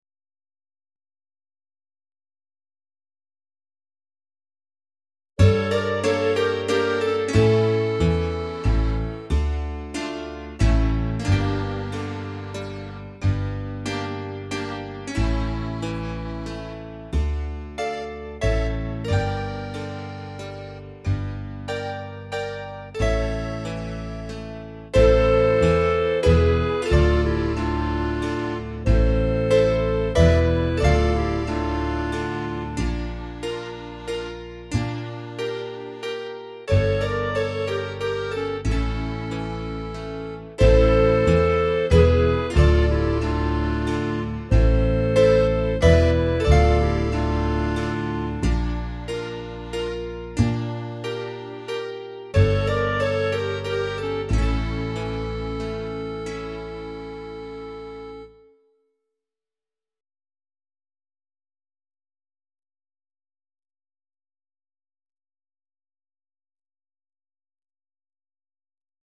45 Tsamikos (Backing Track)